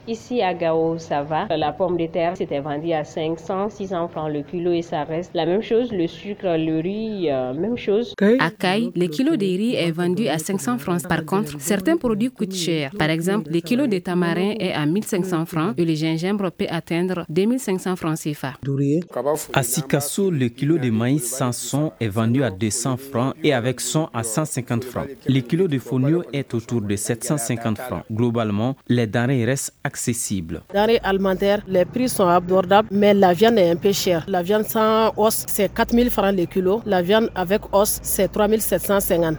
02-VOX-POP-DENREES-REGIONS-FR.mp3